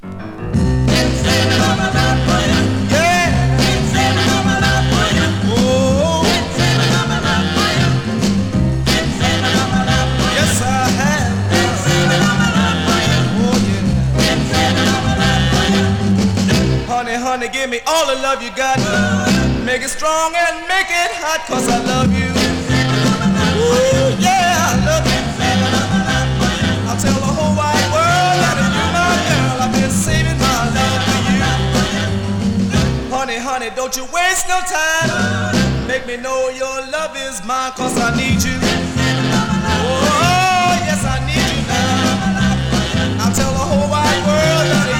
Rhythm & Blues, Rock & Roll 　USA　12inchレコード　33rpm　Mono